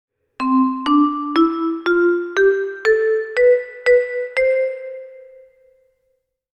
groep5_les1-6-2_drietoonladder2.mp3